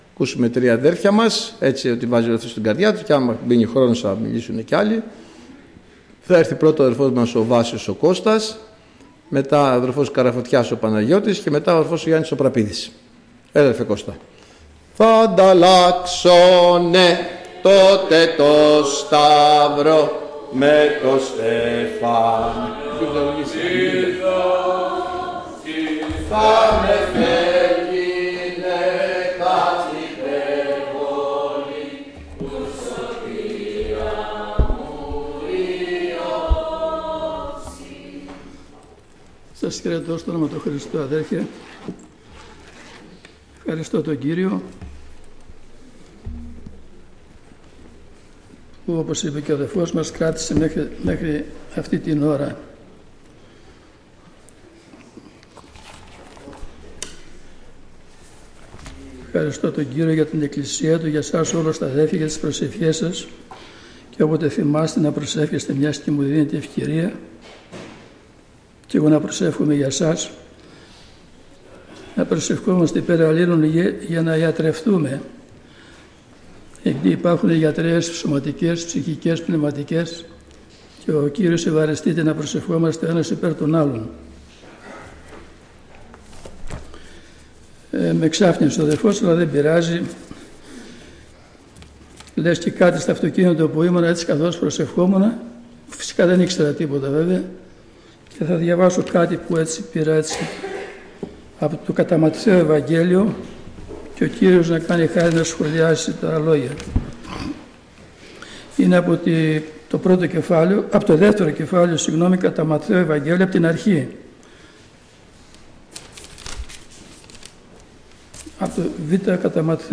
Μηνύματα Αδερφών Ομιλητής: Διάφοροι Ομιλητές Λεπτομέρειες Σειρά: Μηνύματα Ημερομηνία: Παρασκευή, 26 Δεκεμβρίου 2025 Εμφανίσεις: 23 Γραφή: Ματθαίος 2:1-13; Ματθαίος 5:3-11; 1 Ιωάννη 3:1-3 Λήψη ήχου Λήψη βίντεο Κατά Ματθαίον β' [2] 1-13 1.